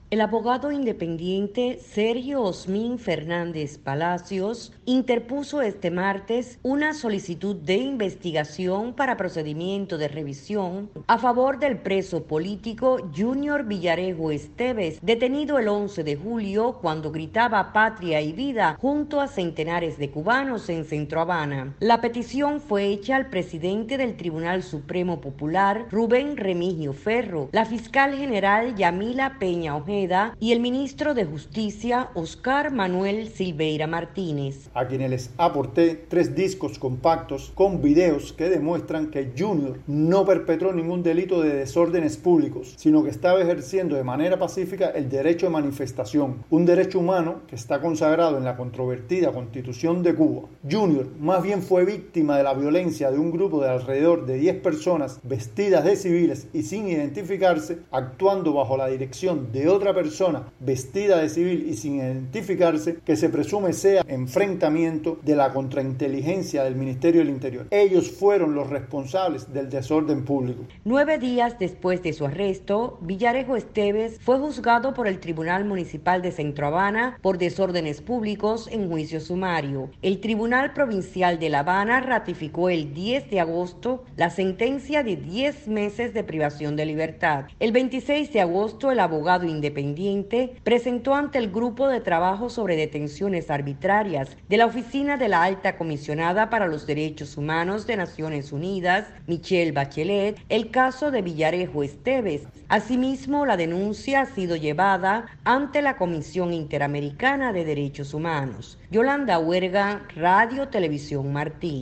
entrevisto